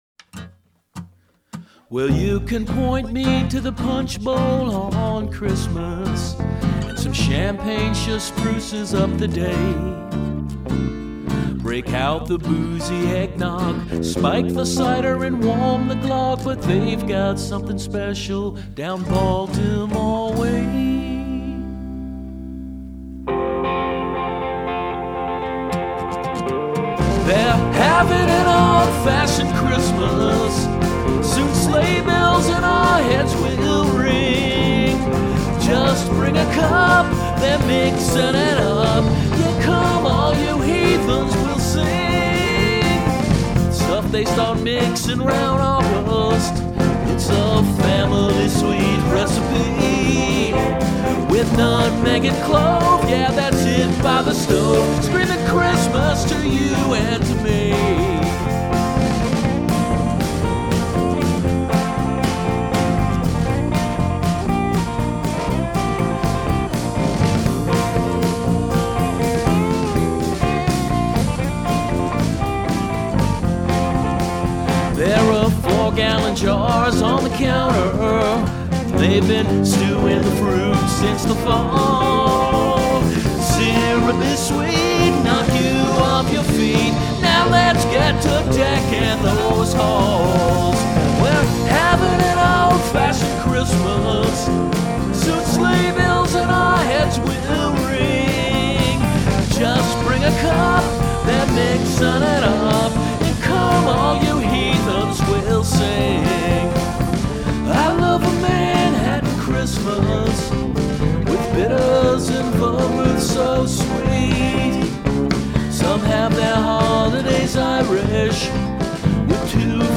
Well, here they are in all their ancient, weirdly mixed and overstuffed glory.